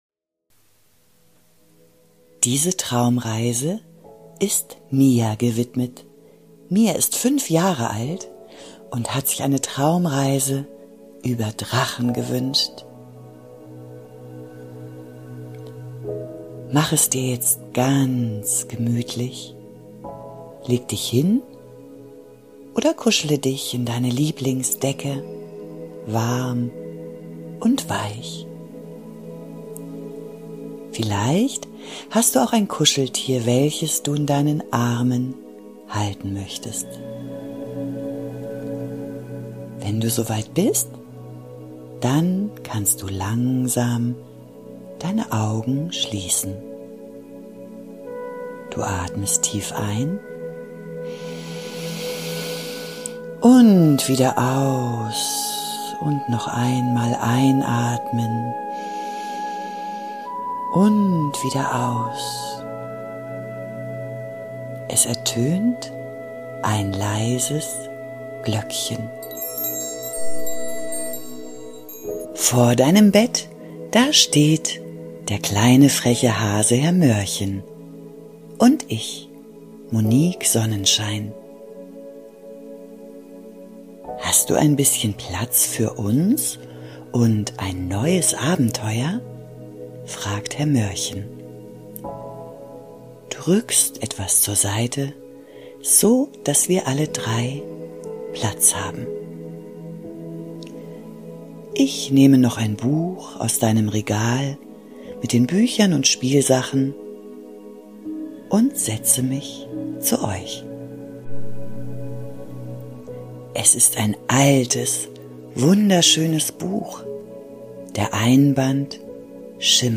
Eine liebevolle Einschlafgeschichte für Kinder ab 4